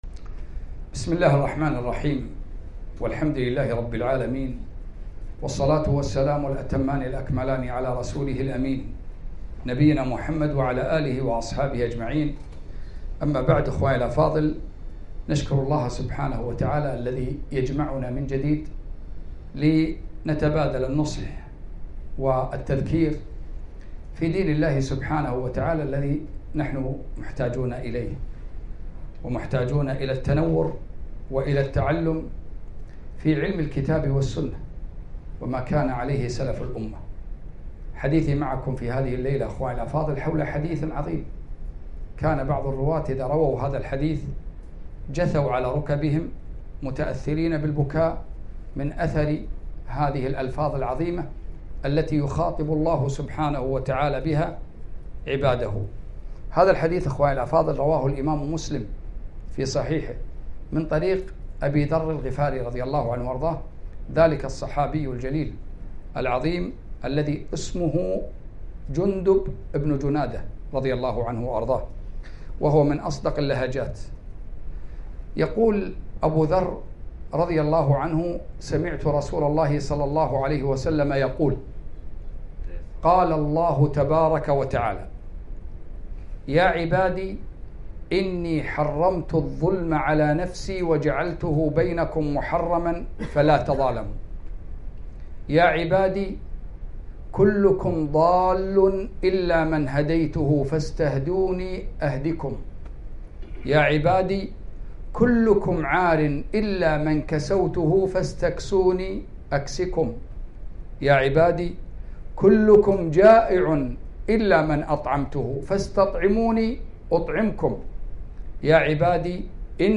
كلمة - وقفات مع الحديث القدسي يا عبادي